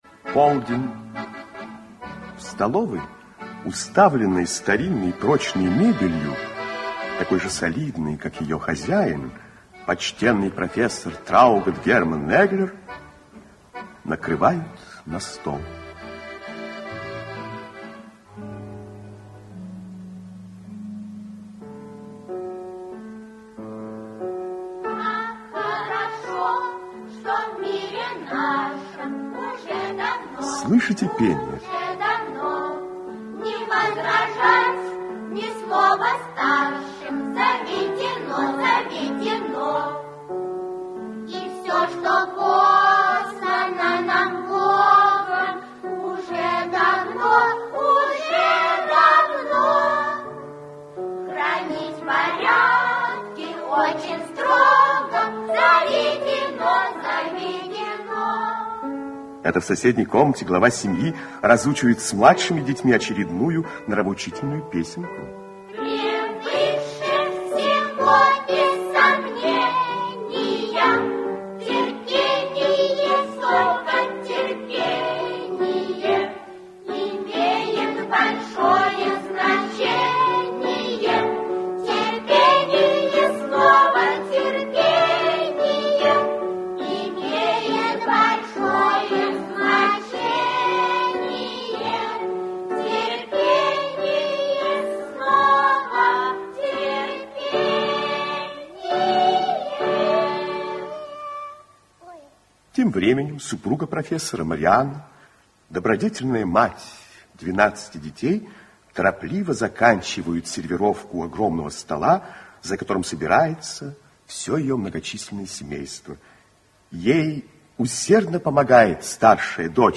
Аудиокнига Покойная тетушка (спектакль) | Библиотека аудиокниг
Aудиокнига Покойная тетушка (спектакль) Автор Курт Гётс Читает аудиокнигу Актерский коллектив.